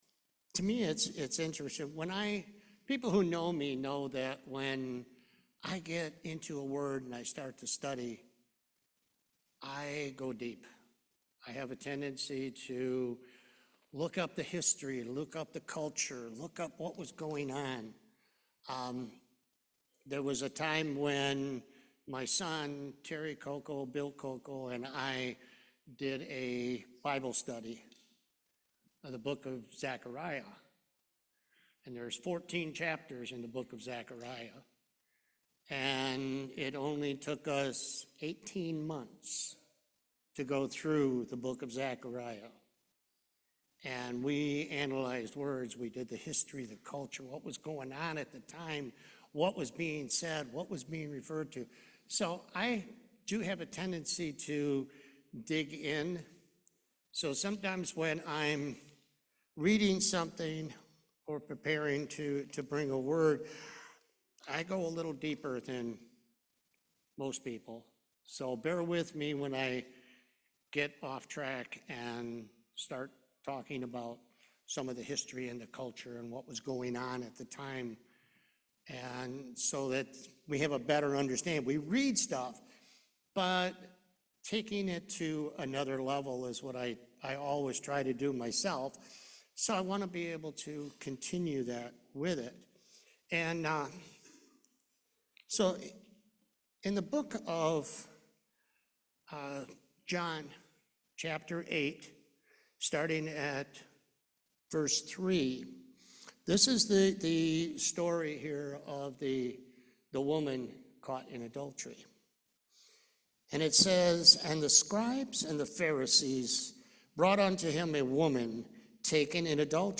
Luke 18:10-13 Service Type: Main Service Repentance means to change your mind or behavior.